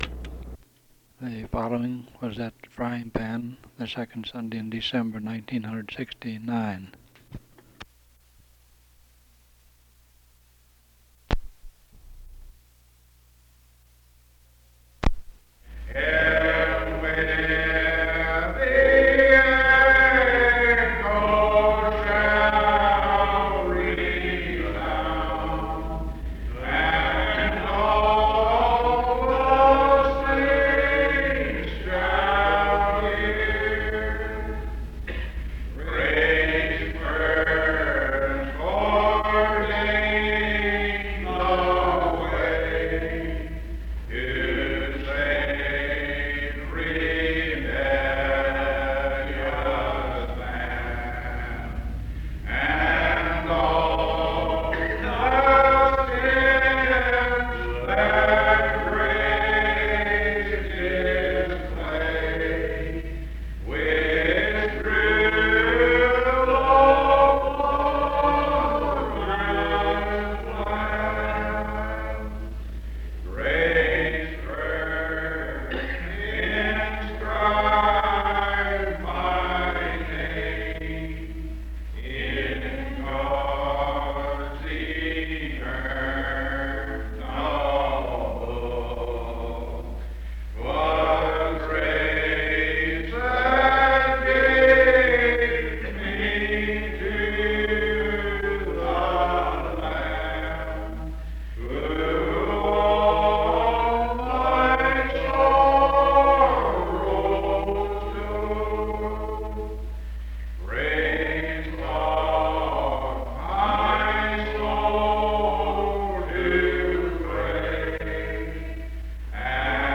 A sermon on election against the teaching of an evangelist